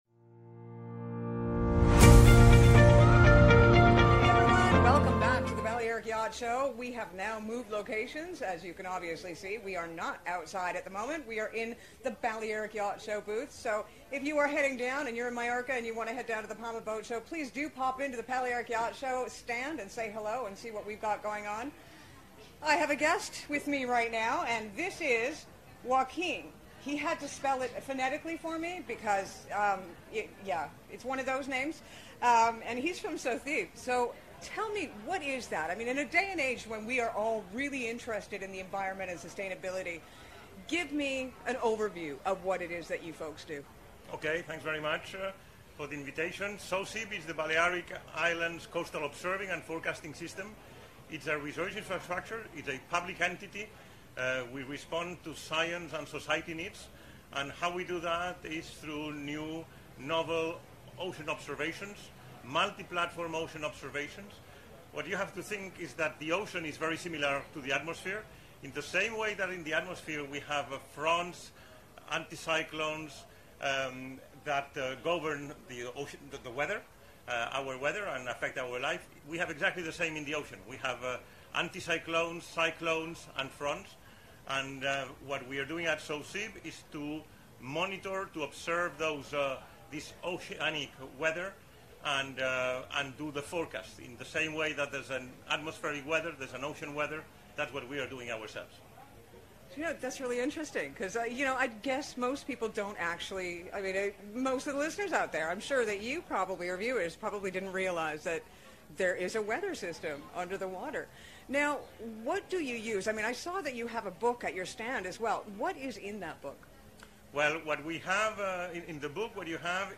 If you missed some of the great interviews that were had during The Balearic Yacht Show, not to worry, we will be bringing you a few of them here!